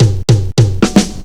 FILL 4    -L.wav